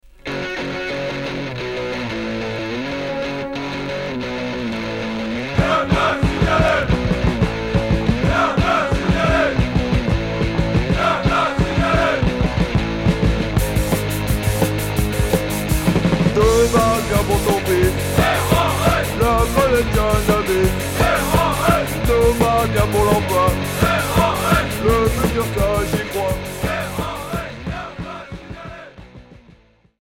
Punk oi